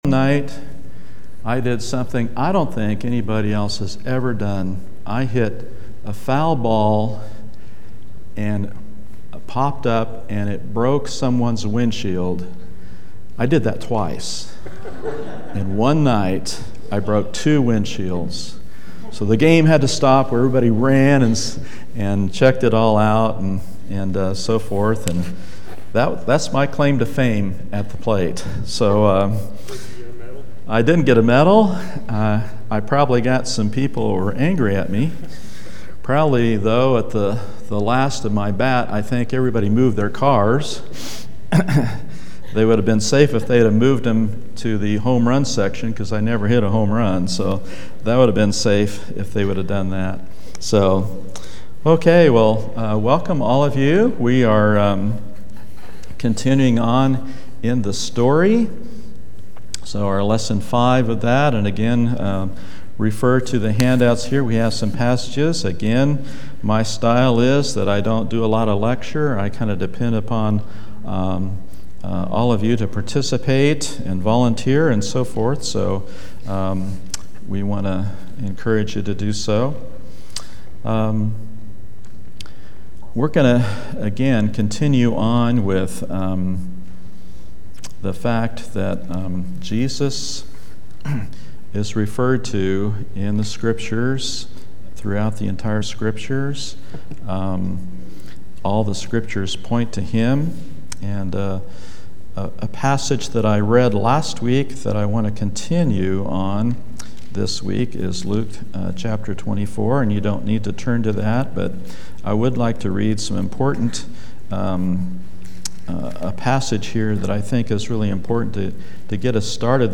In this Adult Sunday School class